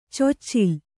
♪ coccil